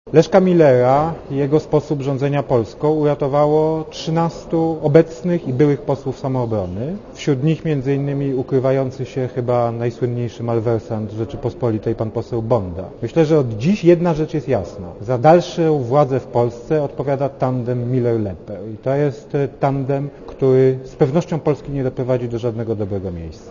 Samoobrona uratowała rząd Leszka Millera - tak Jan Rokita z Platformy Obywatelskiej komentuje sejmowe głosowanie nad wnioskiem o udzielenie rządowi wotum zaufania.
rokitasamoobrona.mp3